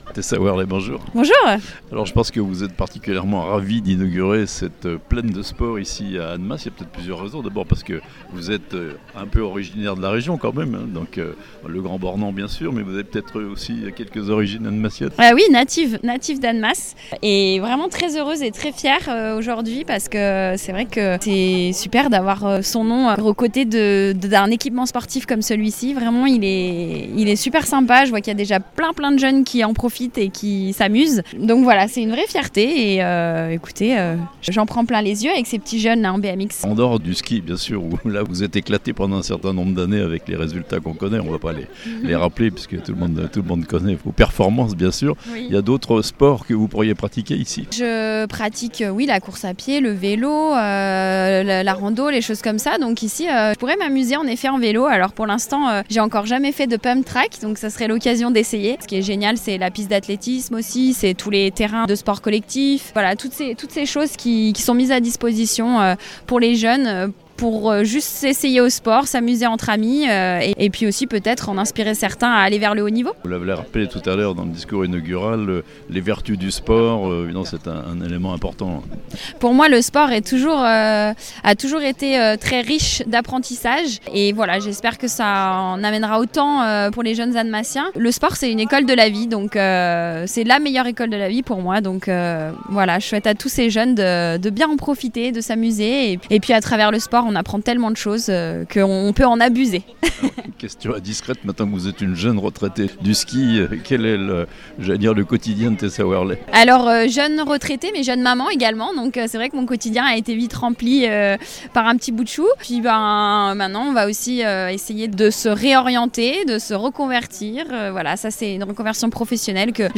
Tessa Worley, au micro de La Radio Plus il y a quelques jours pour l'inauguration de cette plaine des sports :